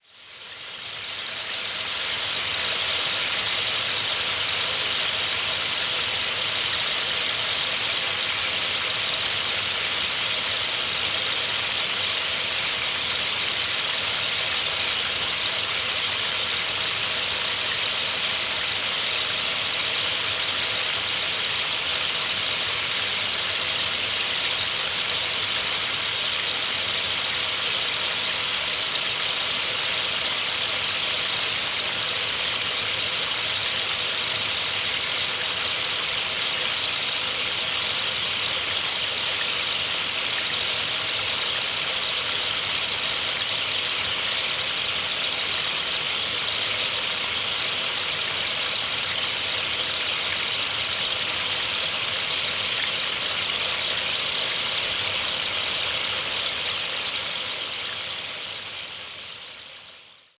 Meinl Sonic Energy Rainmaker, ≈ 30 min, Iroko (RM3IRO)